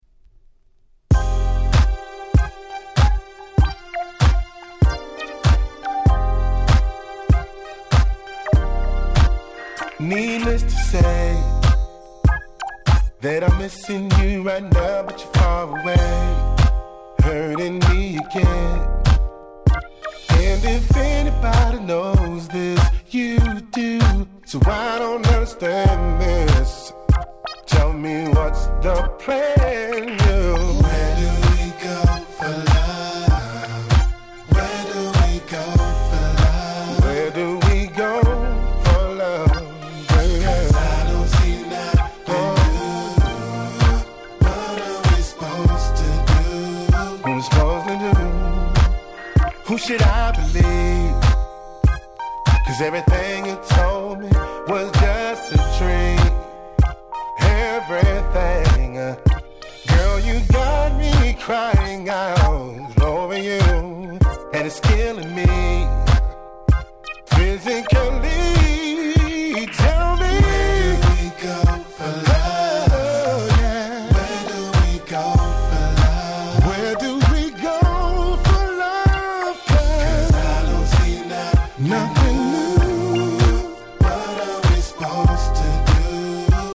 05' マイナーR&B良曲！！